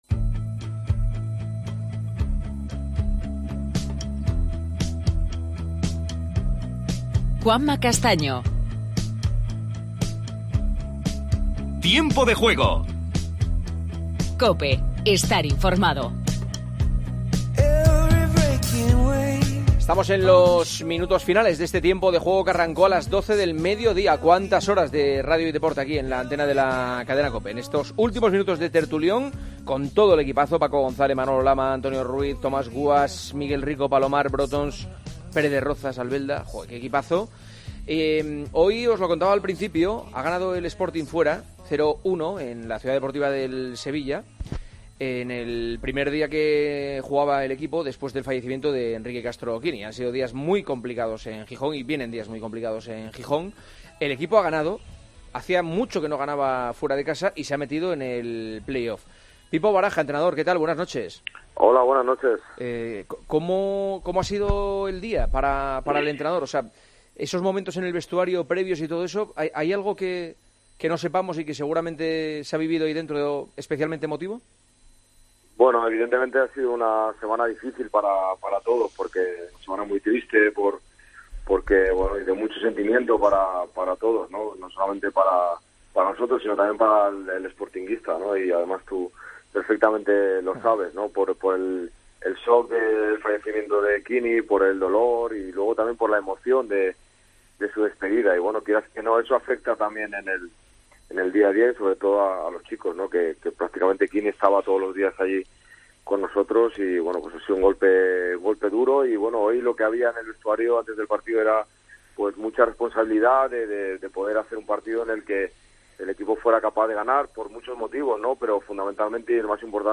Entrevista a Rubén Baraja, entrenador del Sporting. Escuchamos la 'rajada' del entrenador del Alcorcón, Julio Velázquez. Mensajes de los oyentes.